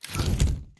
umbrella1.wav